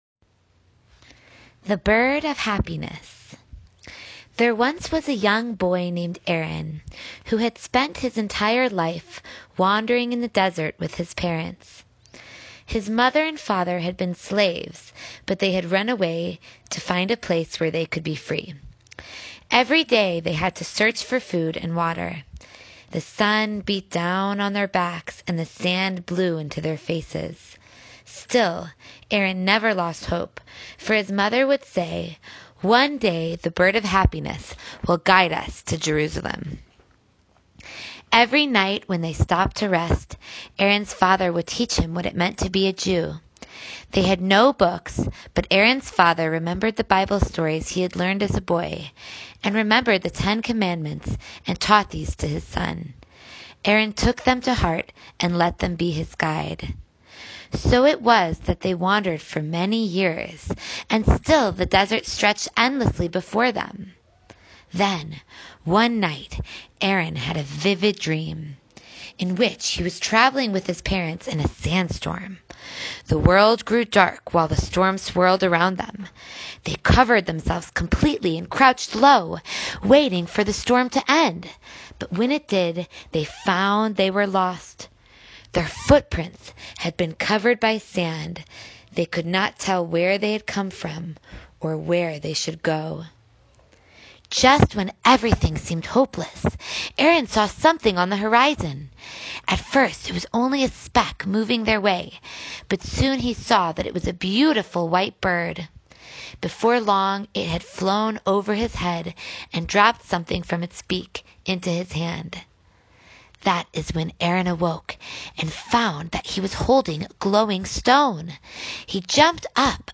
Sephardic Storyteller - Journey to the Mizrah
The 4th story (PPT SLIDE #9) is a 10 min story that includes a narration and text.